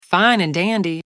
female-Fine and dandy.wav